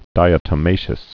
(dīə-tə-māshəs, dī-ătə-)